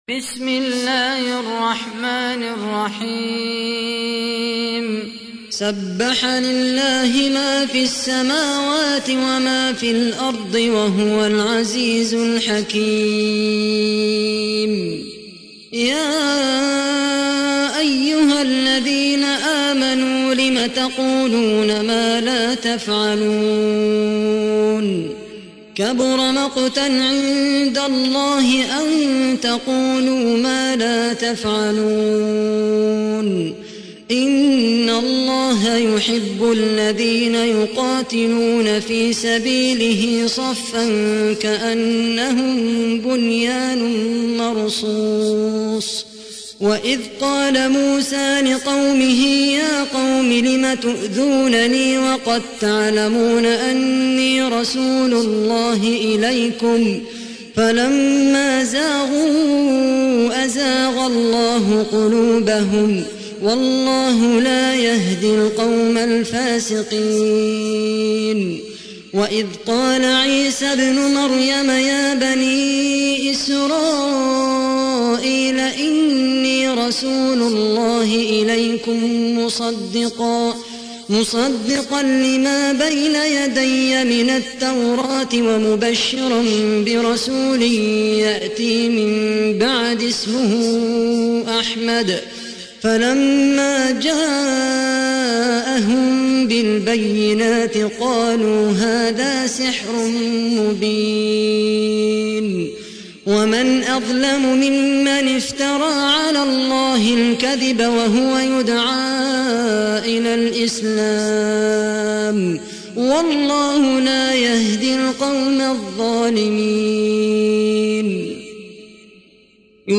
تحميل : 61. سورة الصف / القارئ خالد القحطاني / القرآن الكريم / موقع يا حسين